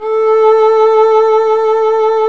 Index of /90_sSampleCDs/Roland - String Master Series/STR_Violin 1-3vb/STR_Vln1 _ marc